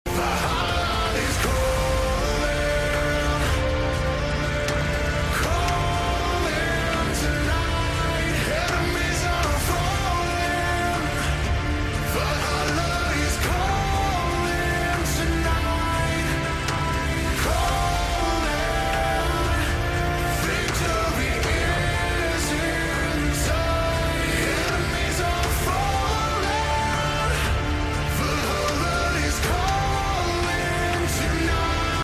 cover
theme song